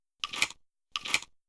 Shotgun Shell Load (Shorter).wav